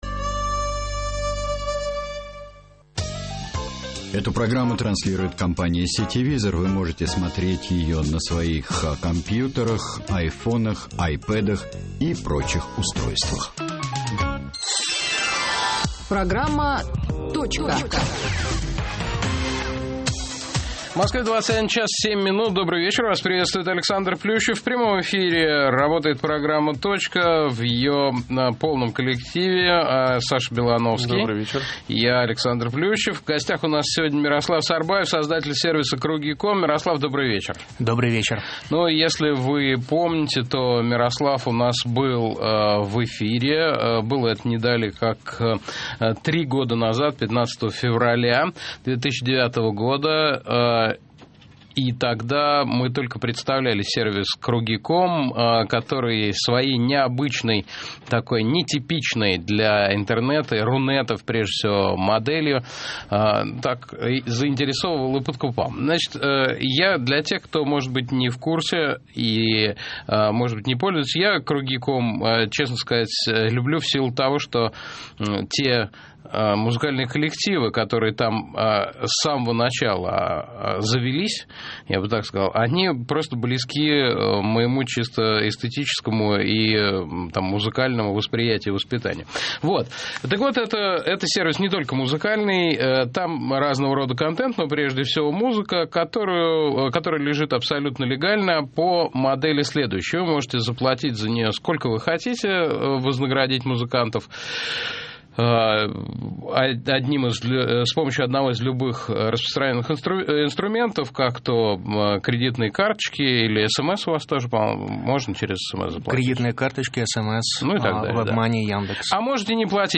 Добрый вечер, вас приветствует Александр Плющев, в прямом эфире работает программа «Точка» в ее полном коллективе.